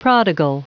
Prononciation du mot prodigal en anglais (fichier audio)
Prononciation du mot : prodigal